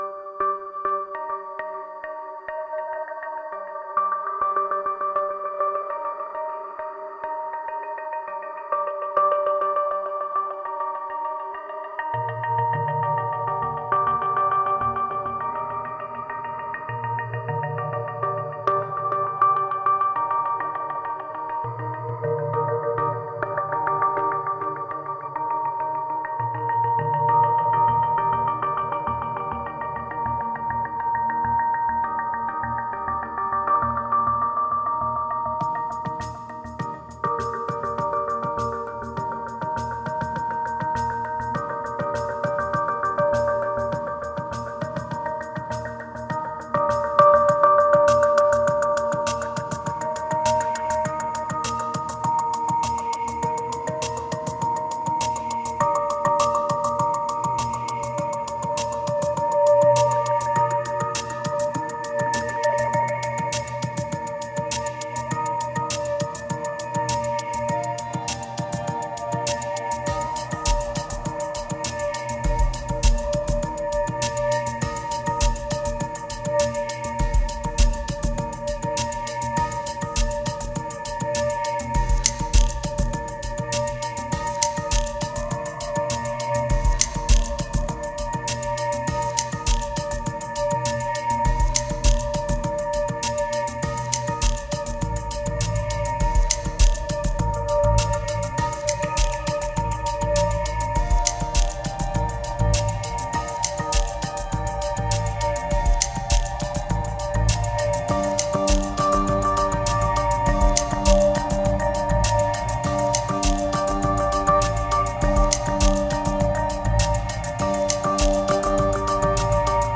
3089📈 - 97%🤔 - 101BPM🔊 - 2013-10-21📅 - 1611🌟
Ambient